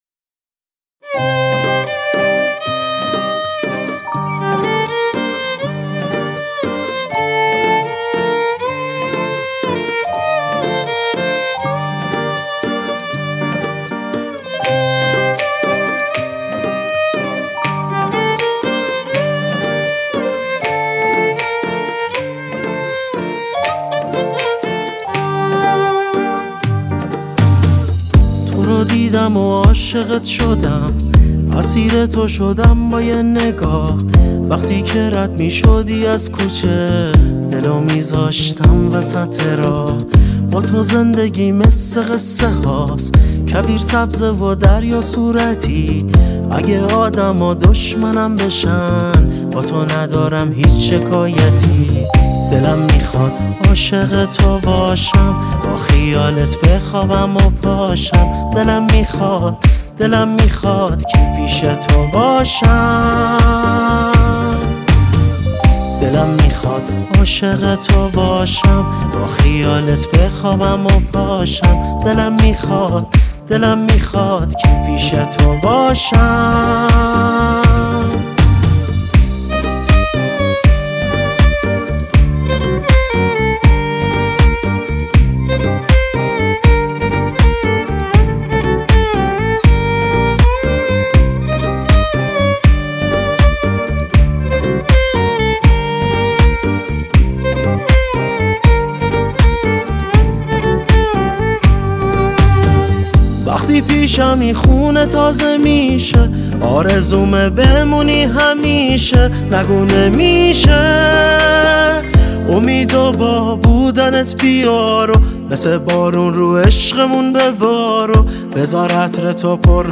⚪ ژانر: پاپ